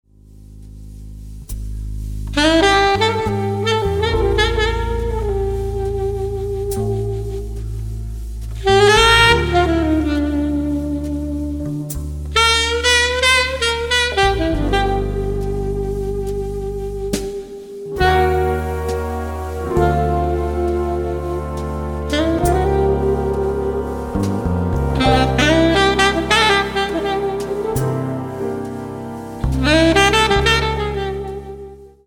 Music from & inspired by the motion picture